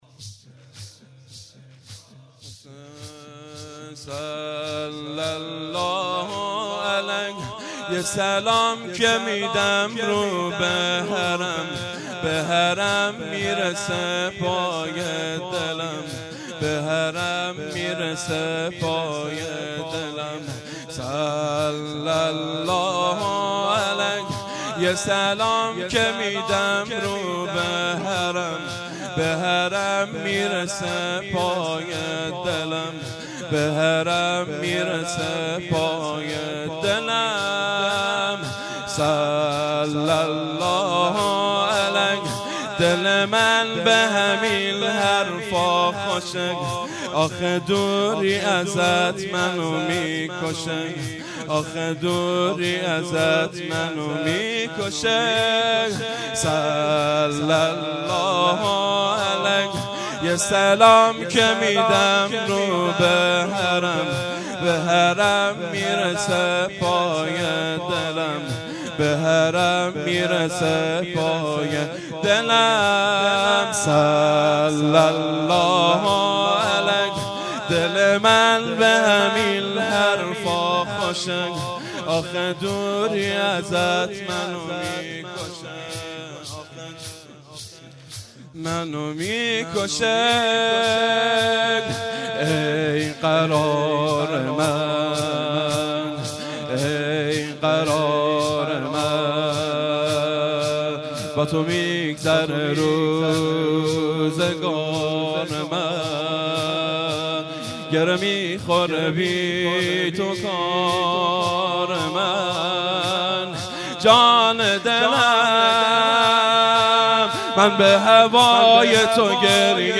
یه سلام میدم به حرم - زمینه